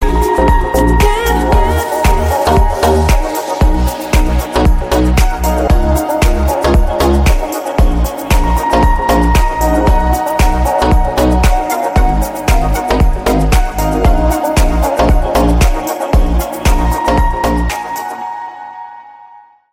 • Качество: 128, Stereo
deep house
мелодичные
пианино
nu disco